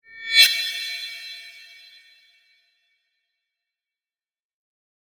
pause-back-click.ogg